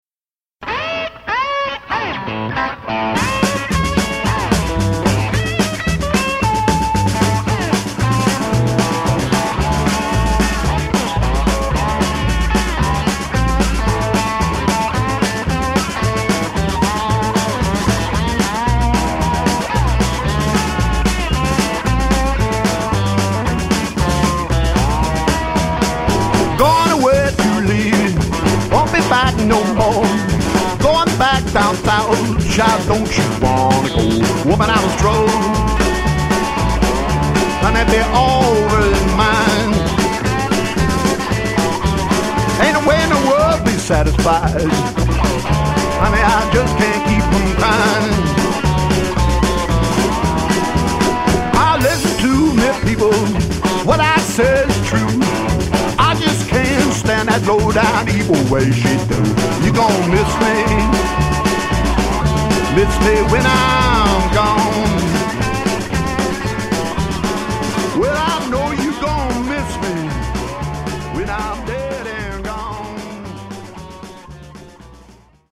Authentic Delta Blues and Roots Music